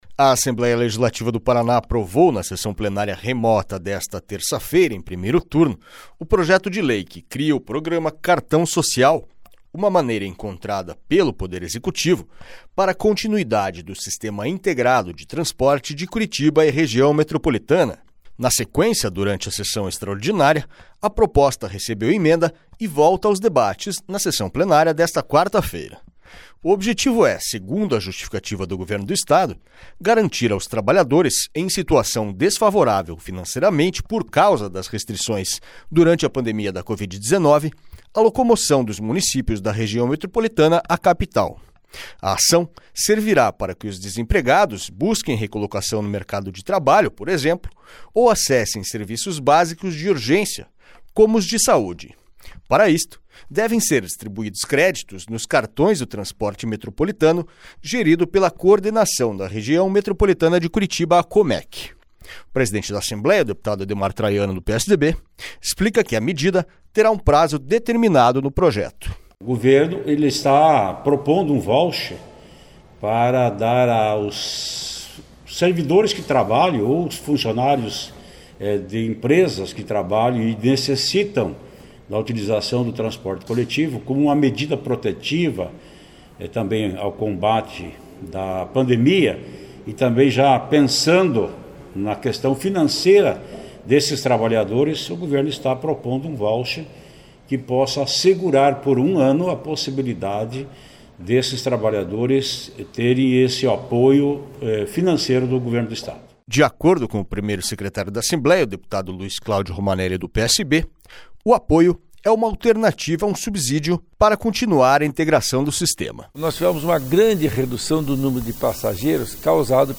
O presidente da Assembleia, deputado Ademar Traiano (PSDB), explica que a medida terá um prazo determinado no projeto.
SONORA ADEMAR TRAIANO
SONORA LUIZ CLÁUDIO ROMANELLI
O deputado Luiz Cláudio Romanelli explica qual o efeito esperado com a iniciativa.